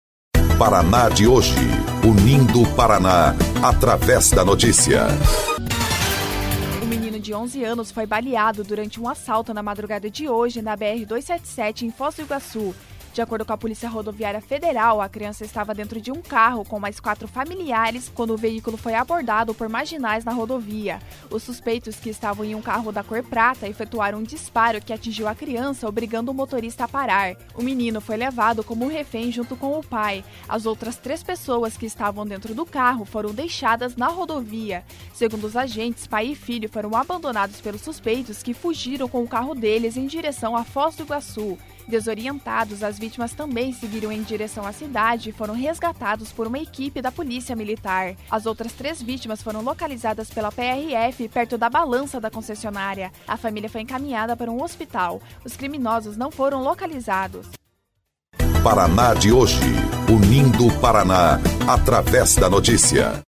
19.01 – BOLETIM – Menino de 11 anos é baleado dentro de carro na BR 277